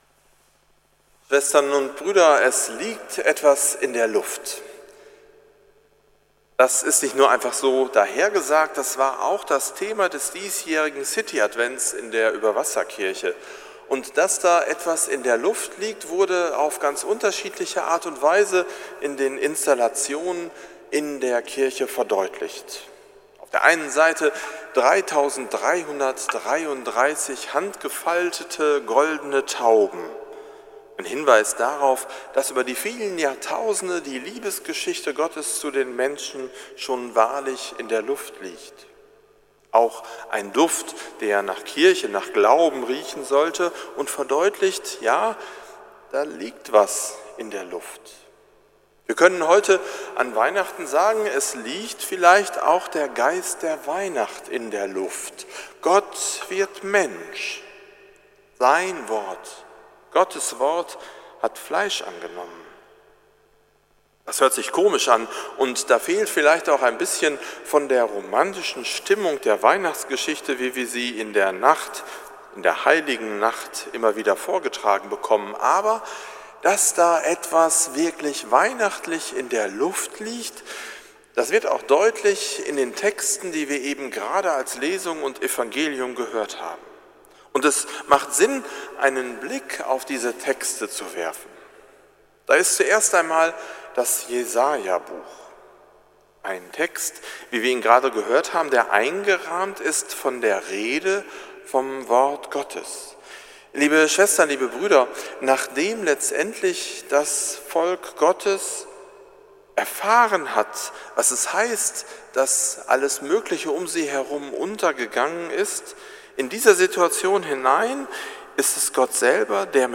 Predigt zu Weihnachten 2017 – St. Nikolaus Münster
predigt-zu-weihnachten-2017